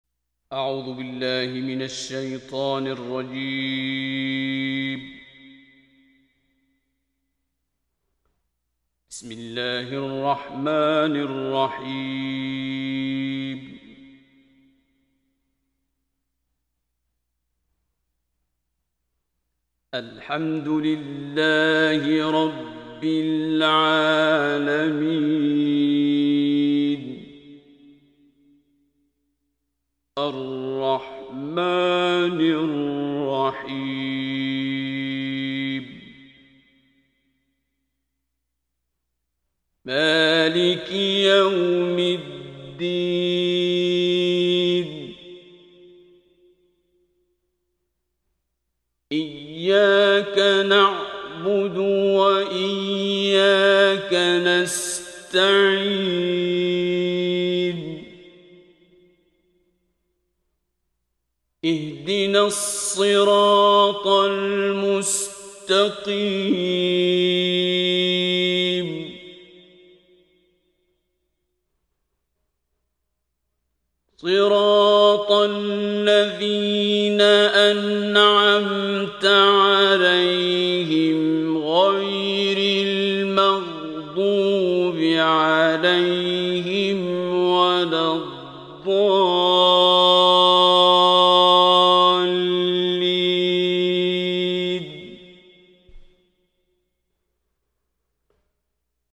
Abdulbasit_Mojawwad-001.mp3